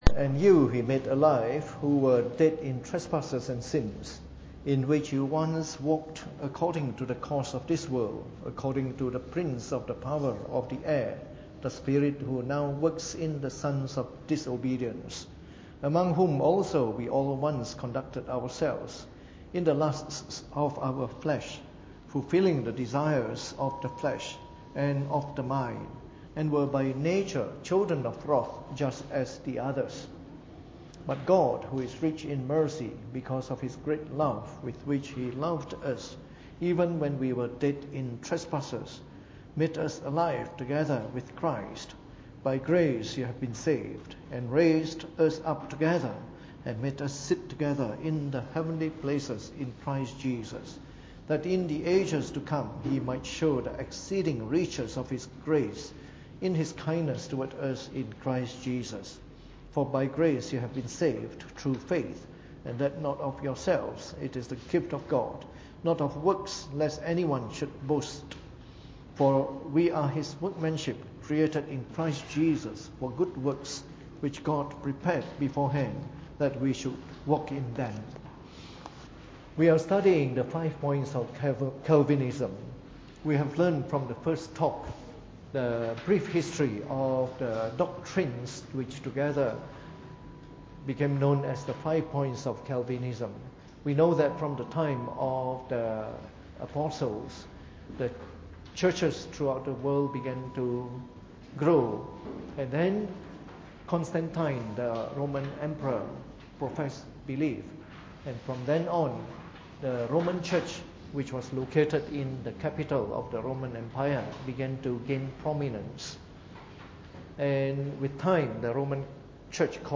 Preached on the 17th of August 2016 during the Bible Study, from our series on the Five Points of Calvinism.